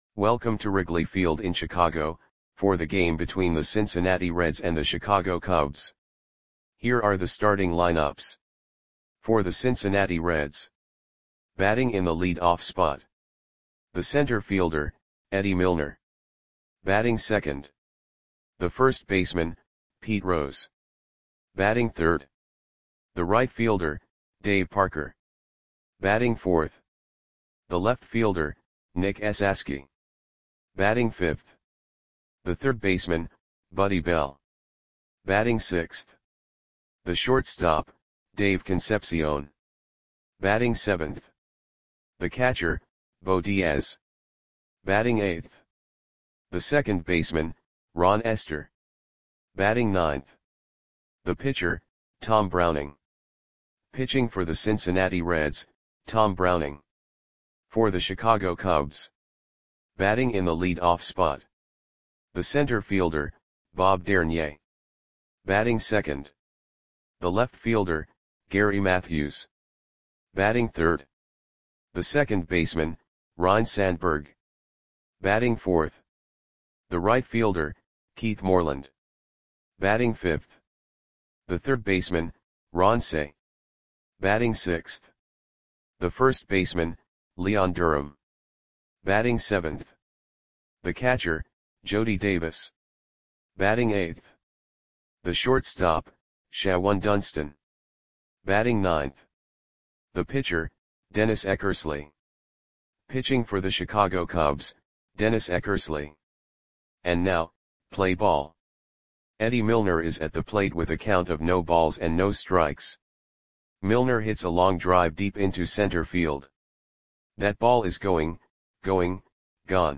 Audio Play-by-Play for Chicago Cubs on September 7, 1985
Click the button below to listen to the audio play-by-play.